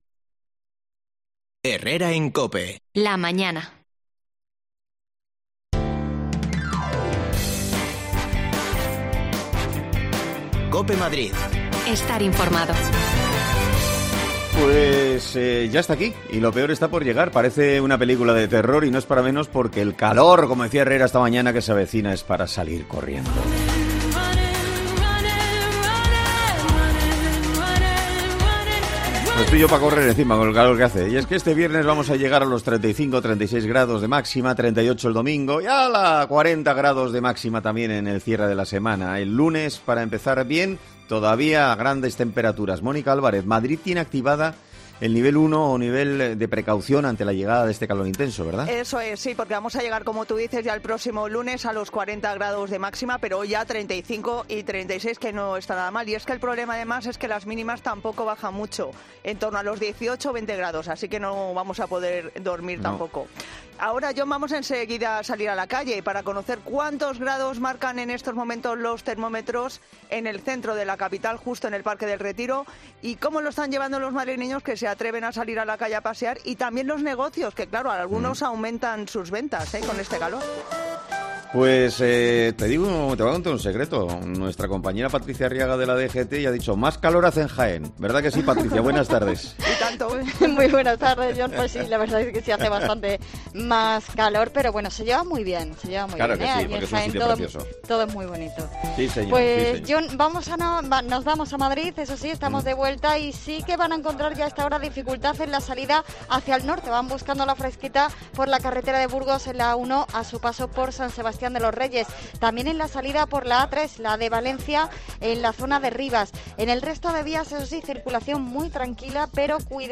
AUDIO: El calor es el gran protagonista de este fin de semana y lo peor está por venir... Salimos a la calle para conocer cómo lo mitigan los madrileños